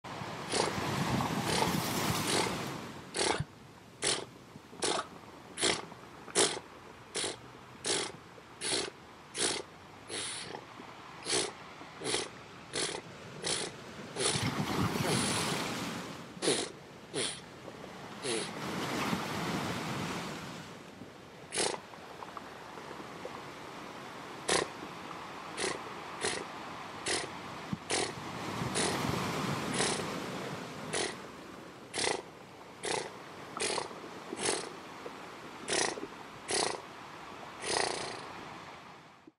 Звук настоящей рыбы фугу, надувающейся на суше под шум океана